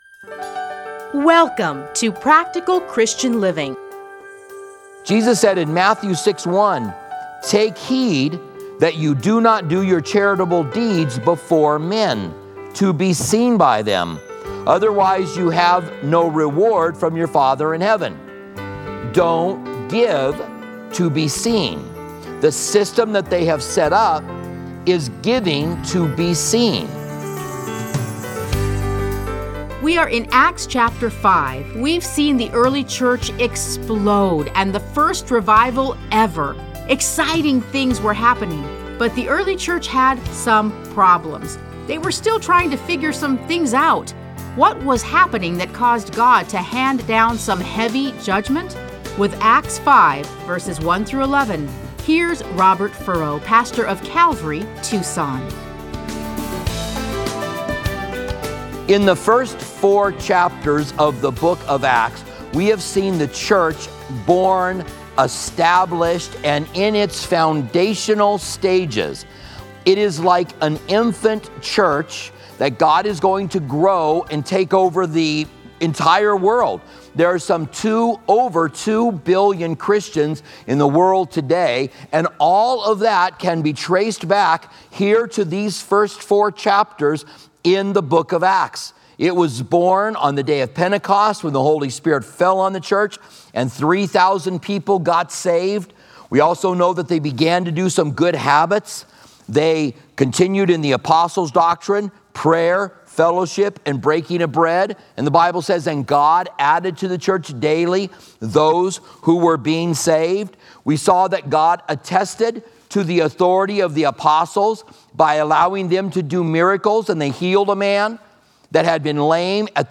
Listen to a teaching from Acts 5:1-11.